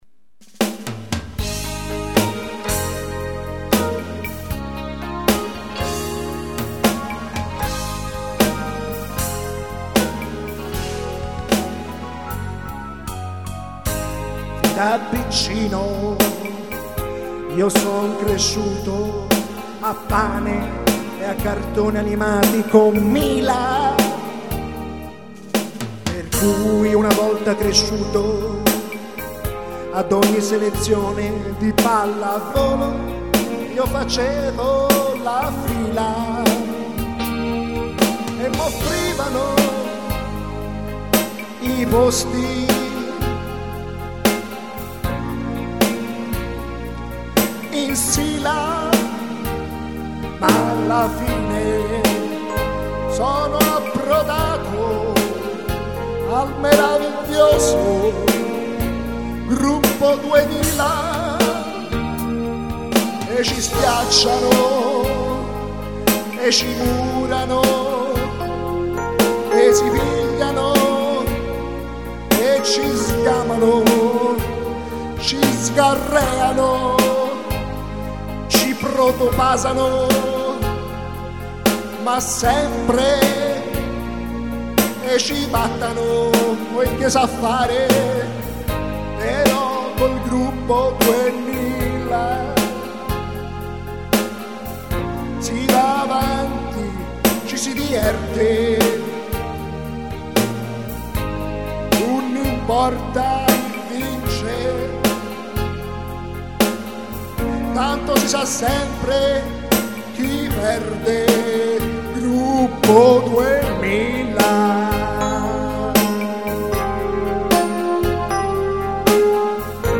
Ottimista e nostalgica (21/02/2007)